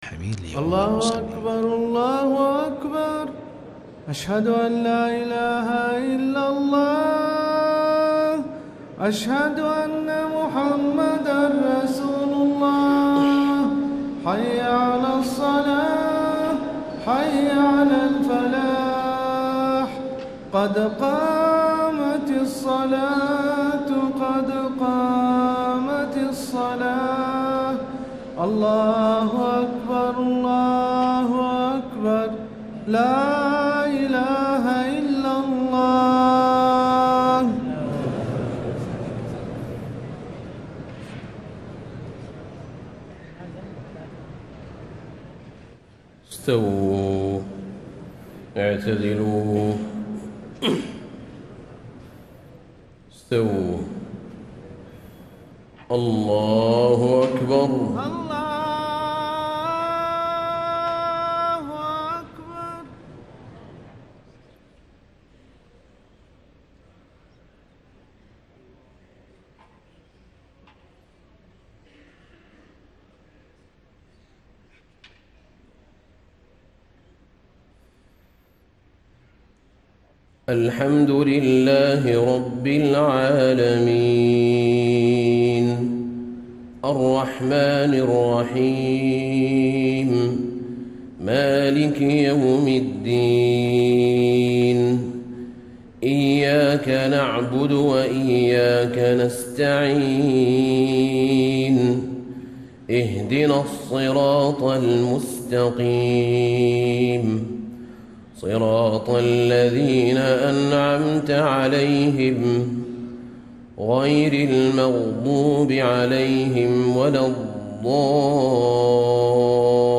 عشاء 27 رمضان سورة الزلزله و التكاثر > 1435 🕌 > الفروض - تلاوات الحرمين